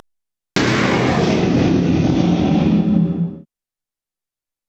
Dinosaur King Acrocanthosaurus Roar
Category: Sound FX   Right: Personal